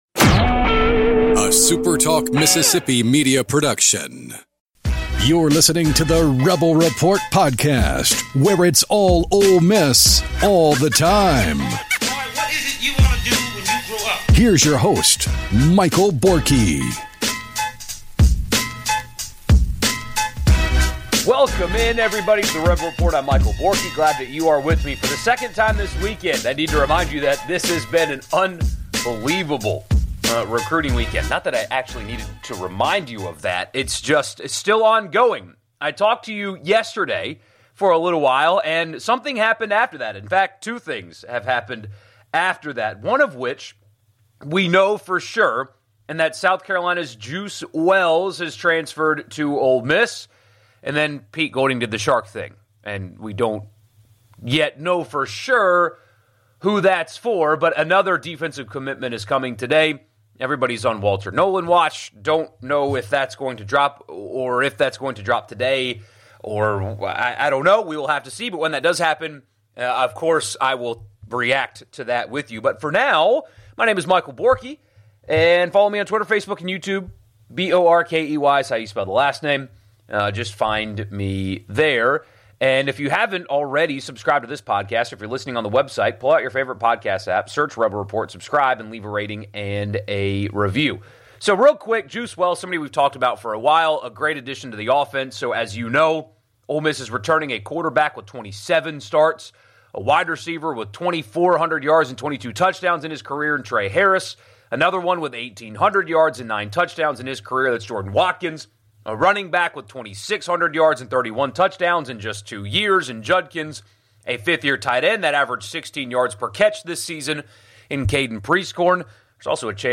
On the Sunday edition of the live chat, we discussed the absolutely insane weekend of transfer portal recruiting that went down in Oxford.